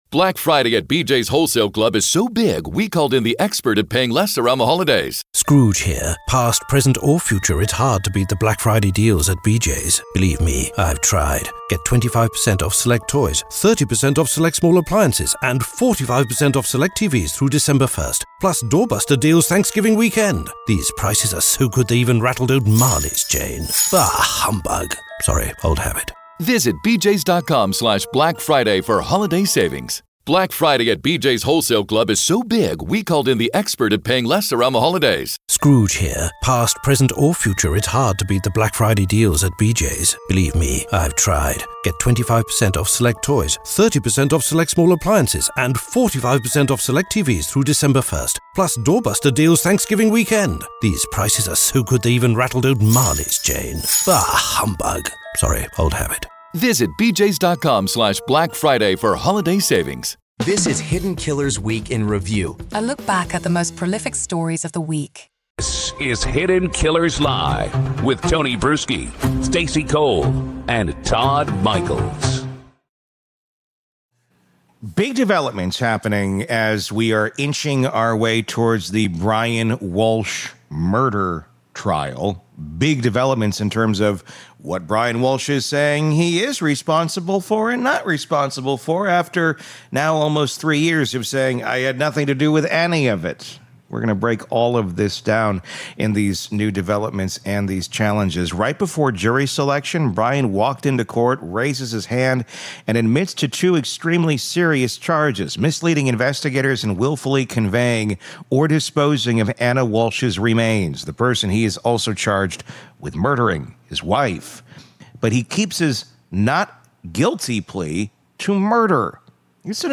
One conversation that lays out the stakes, the law, and the fallout.